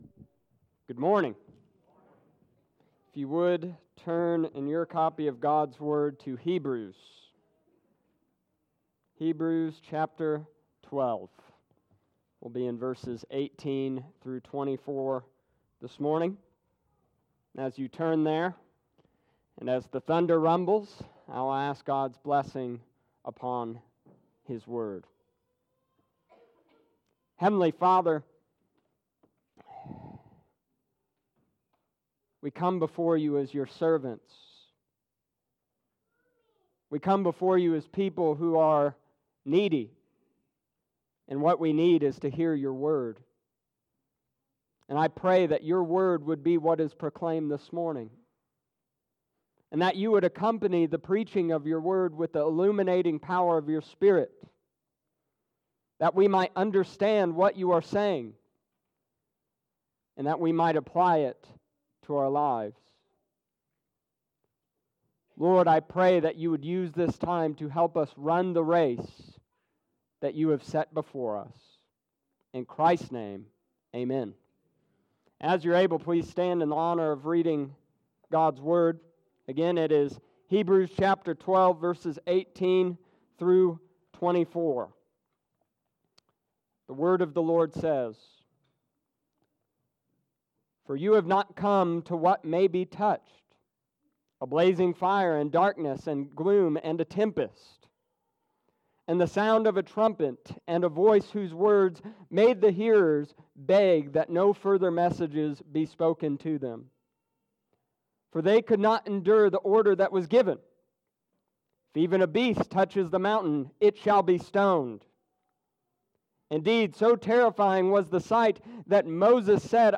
Summary Of Sermon